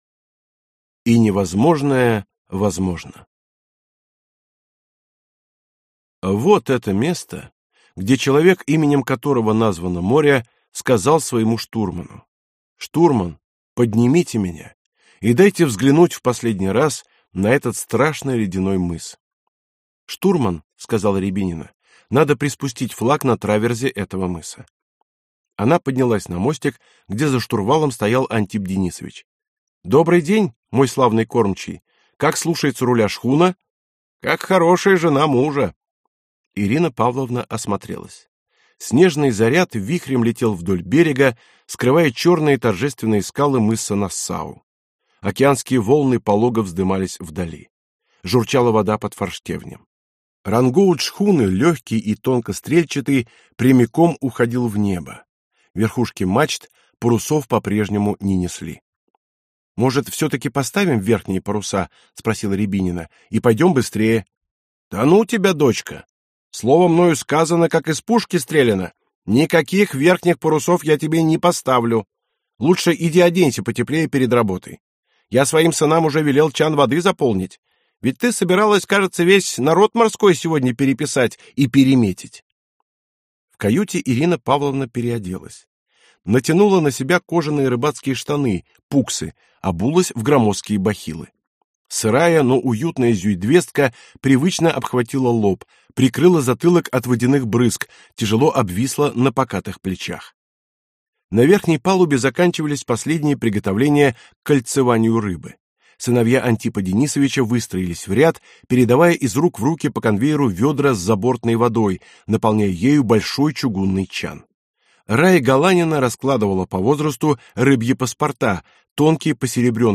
Аудиокнига Океанский патруль. Книга первая. Аскольдовцы. Том 1 | Библиотека аудиокниг